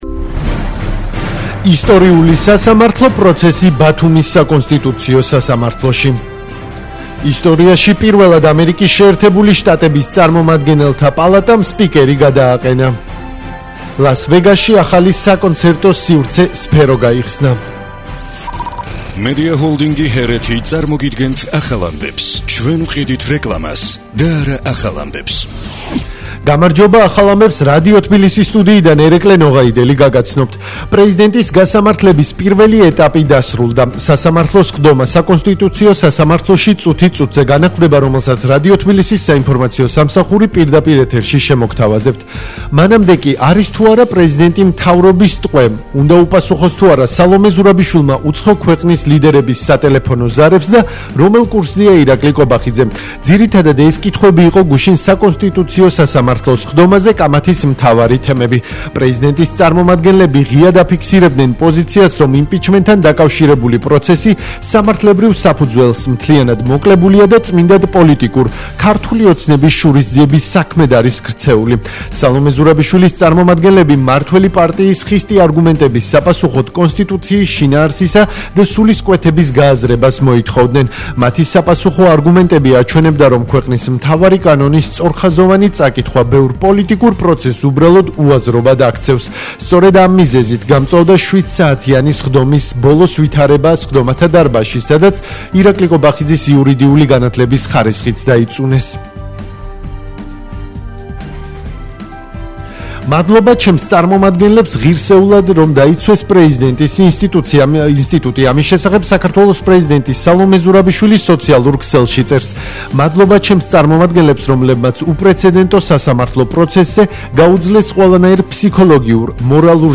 ისტორიული სასამართლო პროცესი ბათუმის საკონსტიტუციო სასამართლოში - პროცესი პირდაპირ ეთერში ისტორიაში
ახალი ამბების სპეციალური გამოშვება 11:00,12:00 – 13:00 საათზე